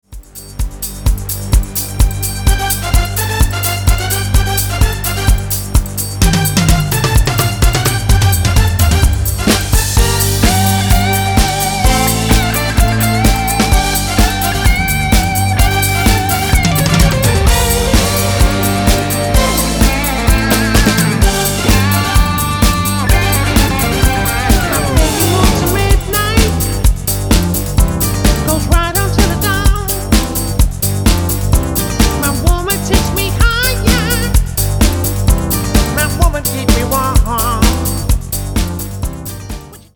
Tonart:Gm Multifile (kein Sofortdownload.